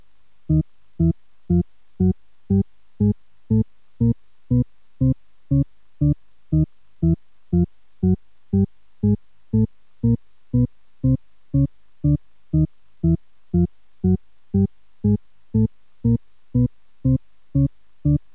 The Shepard Scale
The demostration uses a cyclic set of complex tones, each composed of 10 partials separated by octave intervals.
The result is an "ever-ascending" scale.
Click here to listen to the ascending Shepard scale